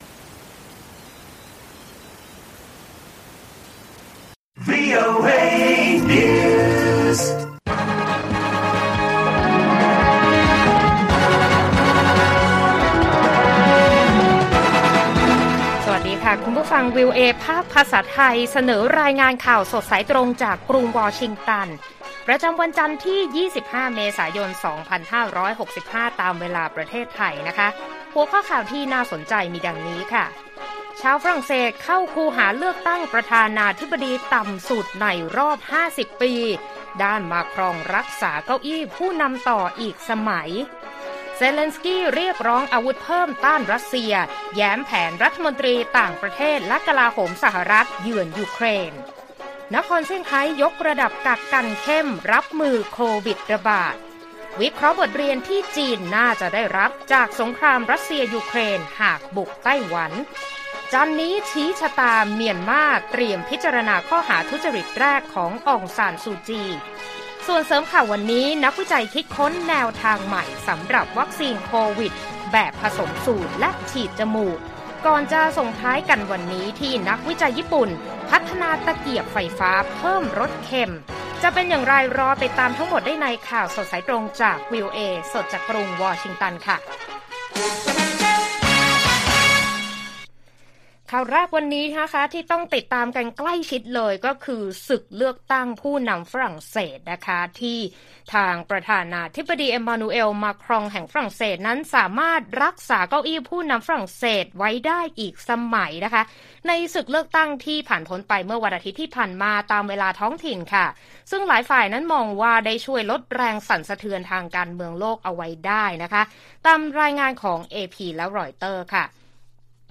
ข่าวสดสายตรงจากวีโอเอไทย วันจันทร์ ที่ 25 เม.ย. 2565